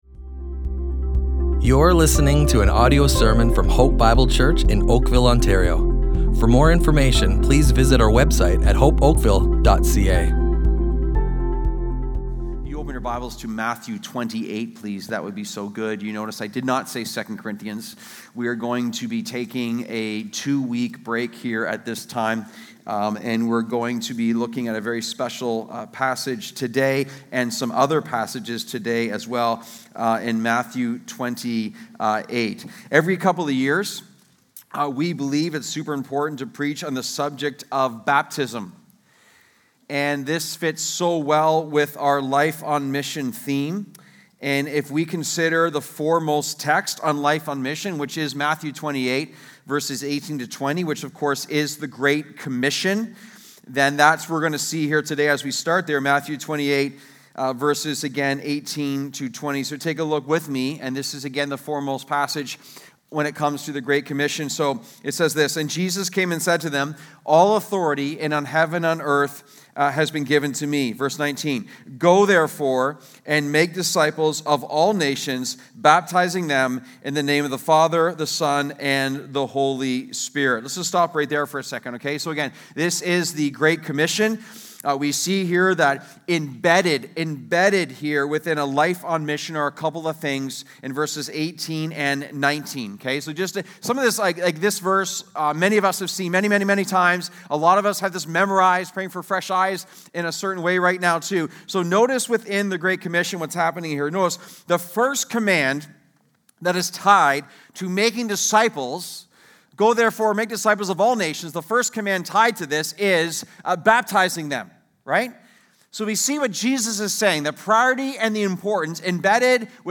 Hope Bible Church Oakville Audio Sermons Life on Mission // Life on Mission Begins with Baptism!